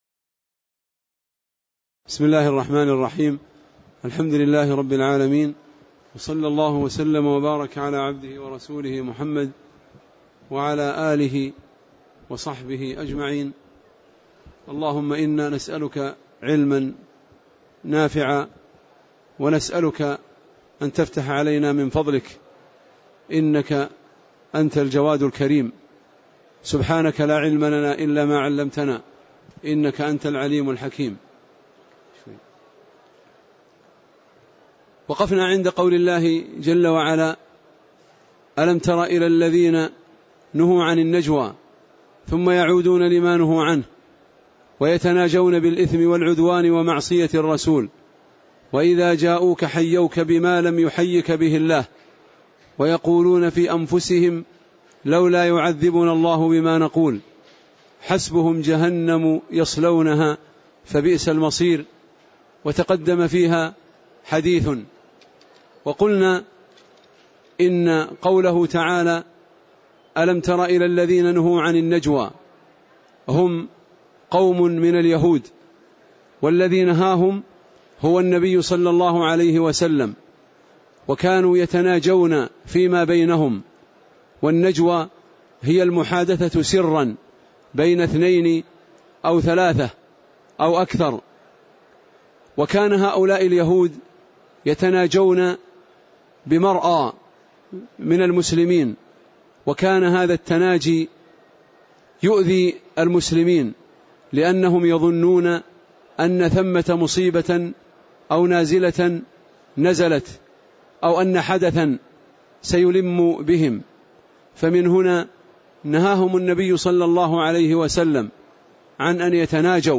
تاريخ النشر ١٧ صفر ١٤٣٨ هـ المكان: المسجد النبوي الشيخ